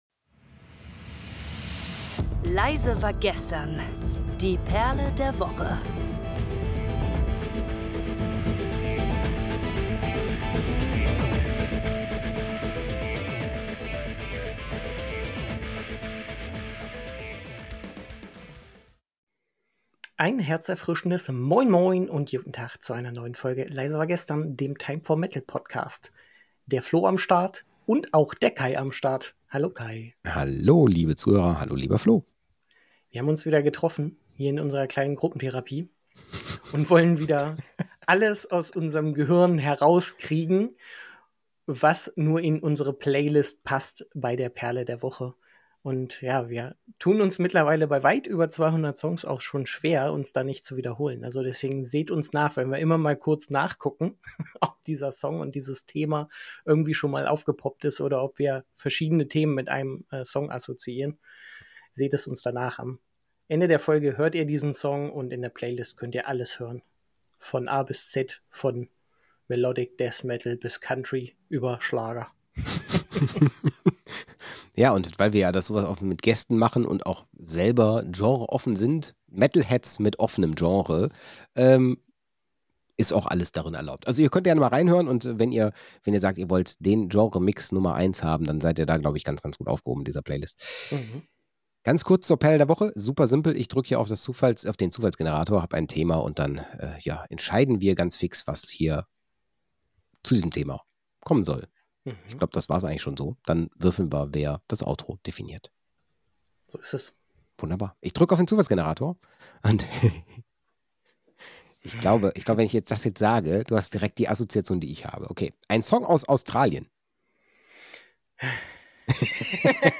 Die Hosts diskutieren, schwärmen und reflektieren – und geben dir nebenbei noch den ein oder anderen Tipp zur Time for Metal-Playlist mit.